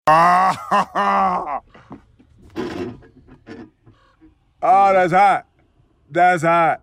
will-smith-thats-hot-meme-256kbps-cbr.mp3